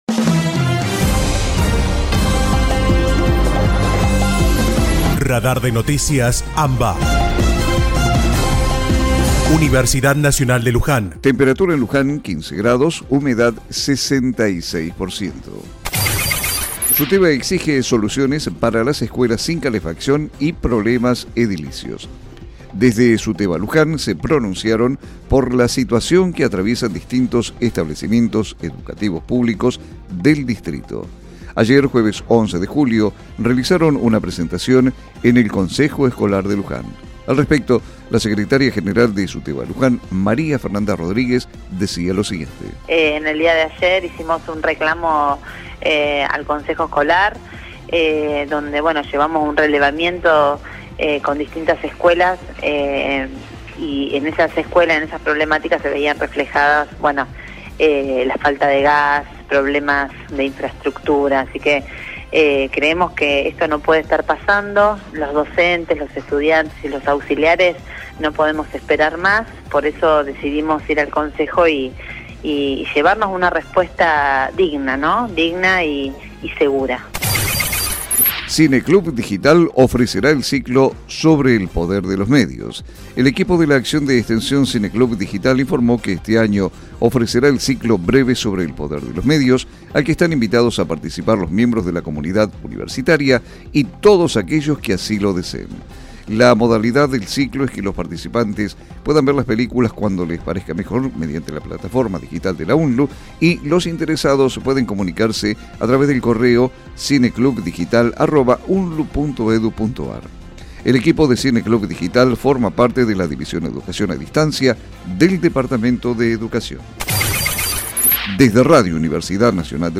Panorama informativo «Radar de Noticias AMBA» , realizado de manera colaborativa entre las emisoras de las Universidades Nacionales de La Plata, Luján, Lanús, Arturo Jauretche, Avellaneda, Quilmes, La Matanza y General Sarmiento, integrantes de ARUNA (Asociación de Radiodifusoras Universitarias Nacionales Argentinas).